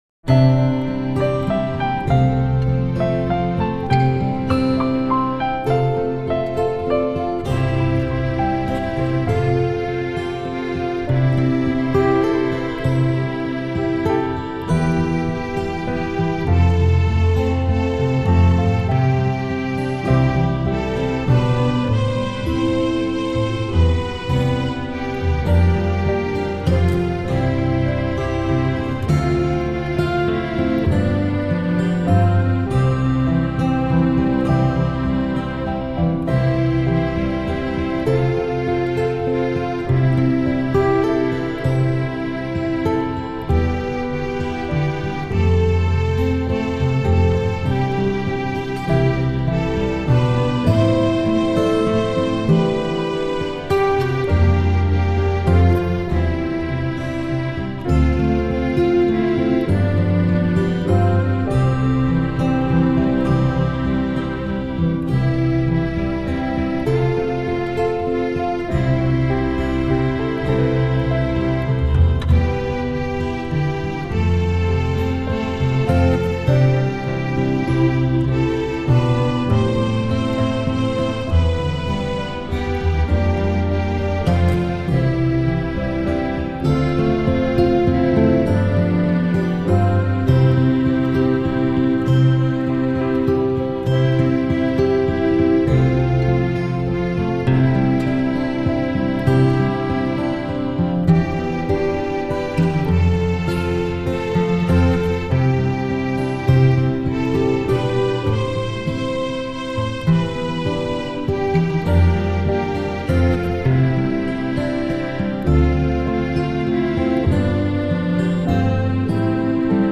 “Go Now in Peace” is by Kevin Keil and is a gentle recessional that is really a sweet singable tune.